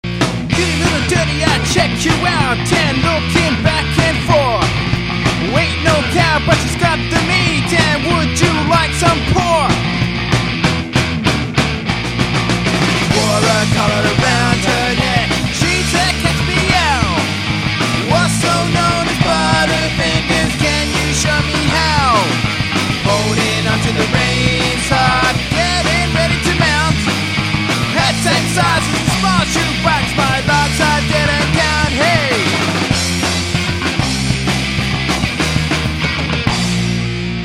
Good quality and recorded by digital multitrack.